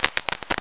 bump1.wav